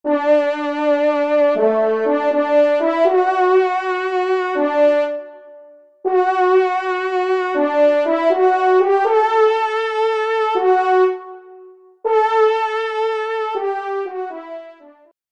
Genre : Fantaisie Liturgique pour quatre trompes
Pupitre 1ère Trompe